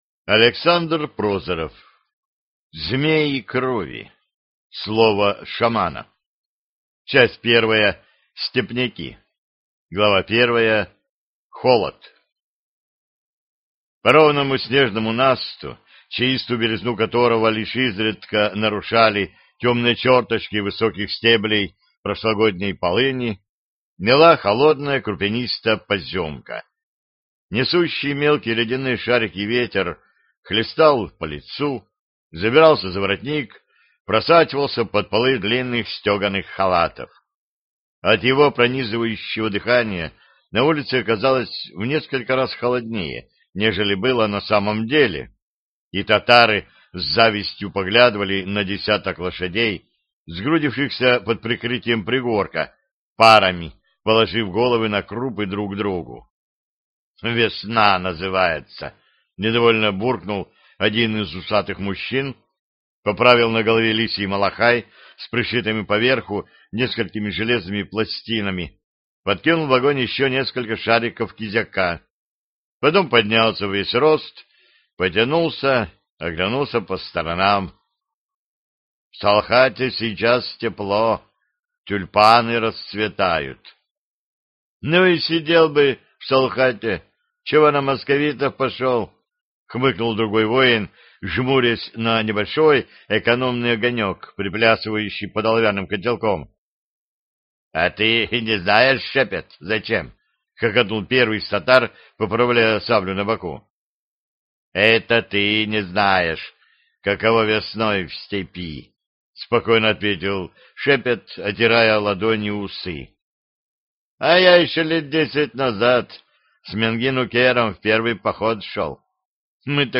Аудиокнига Змеи крови (Слово шамана) | Библиотека аудиокниг